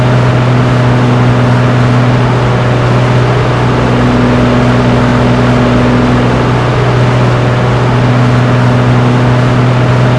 rav4_low.wav